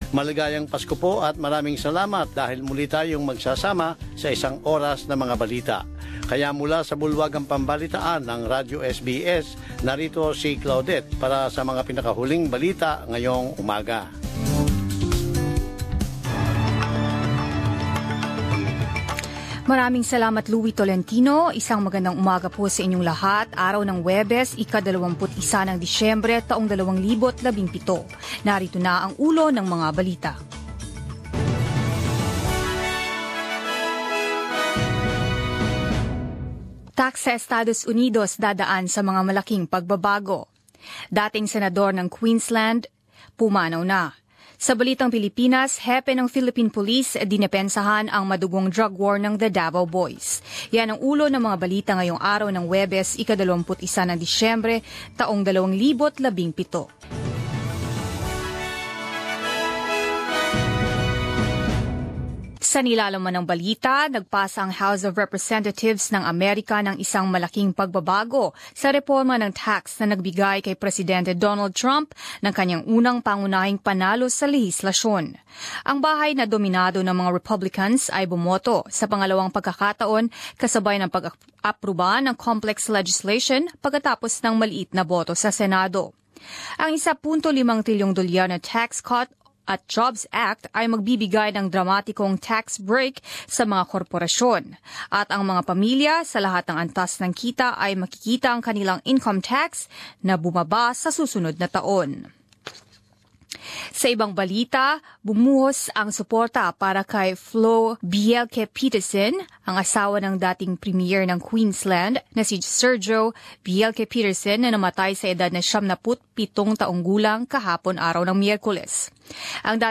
Mga Balita ng 21 Disyembre 2017